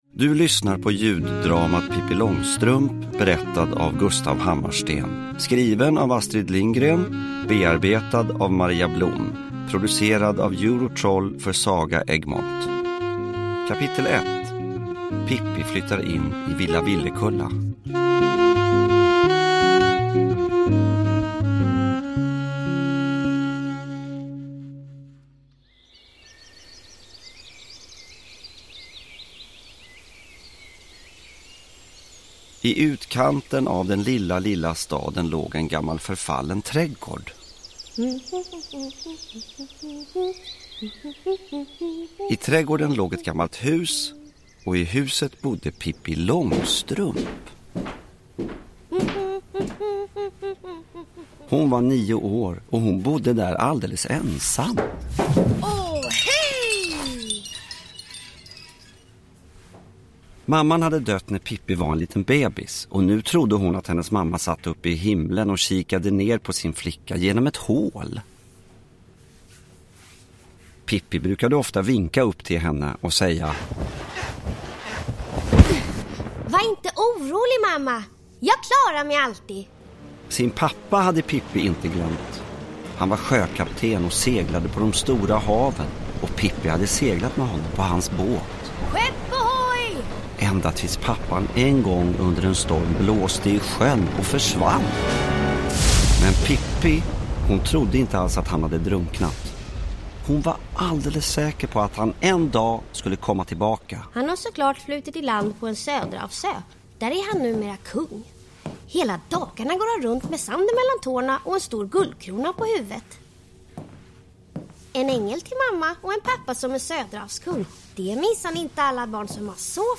Pippi Långstrumps äventyr kommer till liv i ett ljuddrama där de älskade berättelserna av Astrid Lindgren framförs av en rik skådespelarensemble, med Gustaf Hammarsten som berättare. Med livfulla ljudeffekter och originella musikinslag – detta är berättelserna om Pippi så som du aldrig hört dem förut!